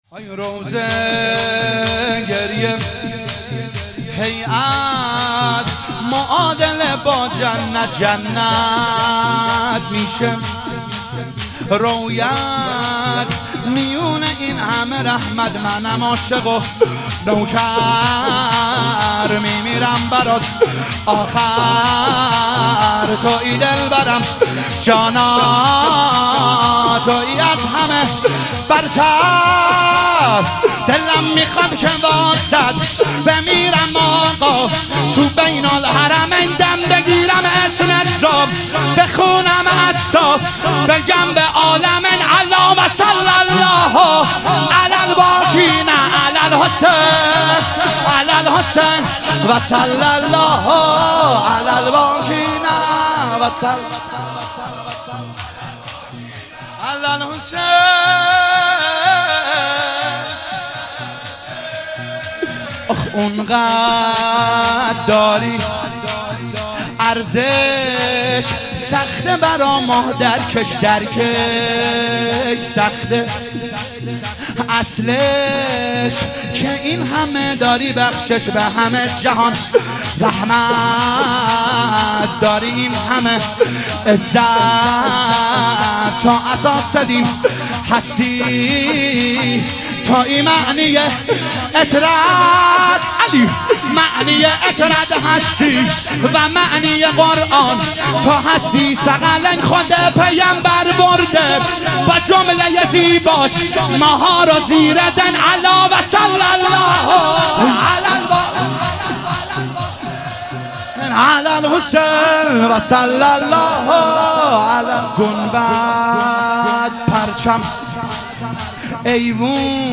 شهادت حضرت خدیجه (س)